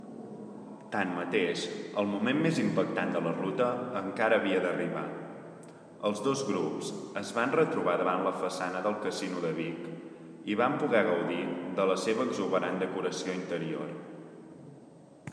Fragment reportatge